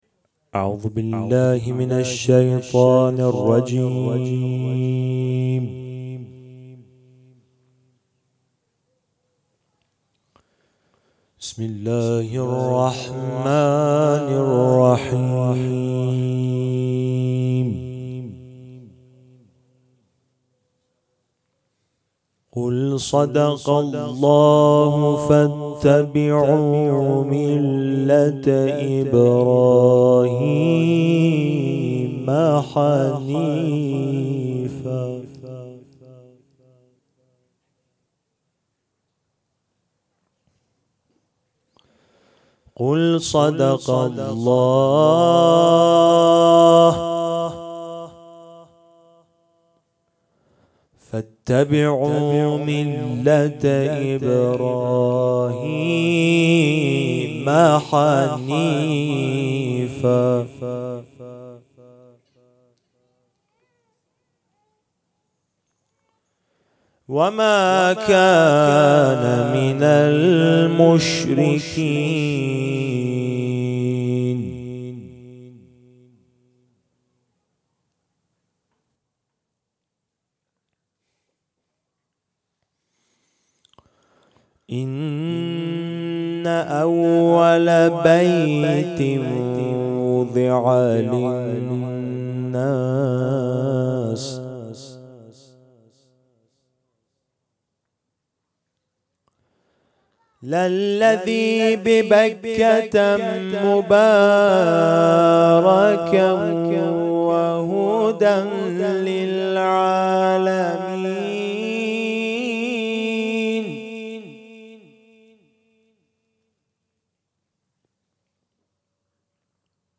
İranlı kâri Al-i İmrân suresinden ayetler tilavet etti
Etiketler: İranlı kâri ، kuran ، tilavet